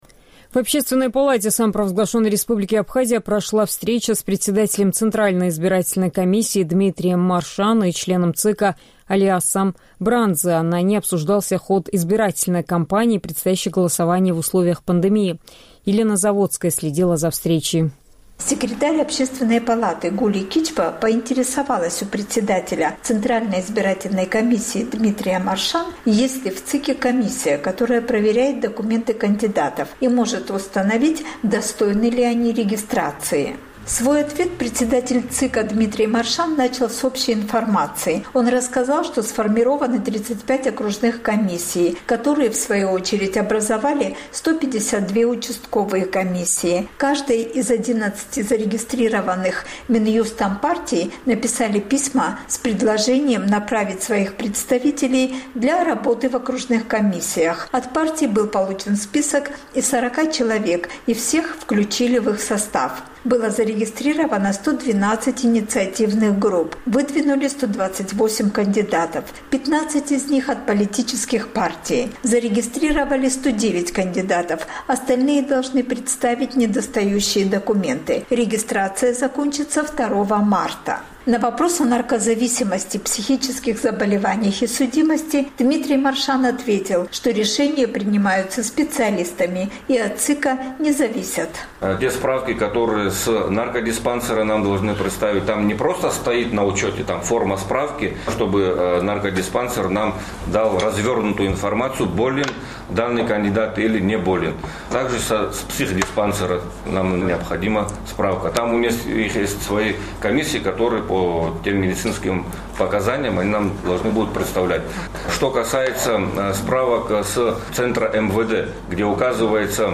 В Общественной палате Абхазии прошла встреча с председателем Центральной избирательной комиссии Дмитрием Маршан и членом ЦИКа Алясом Брандзия. На ней обсуждались ход избирательной кампании и предстоящее голосование в условиях пандемии.